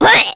Nia-Vox_Damage_kr_03.wav